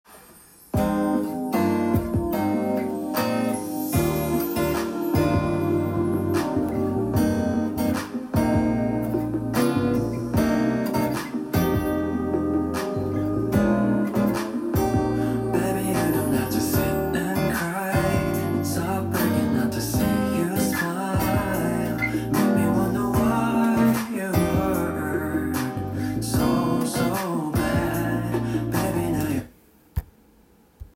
音源にあわせて譜面通り弾いてみました
主にＥ♭ダイアトニックコードで構成されています。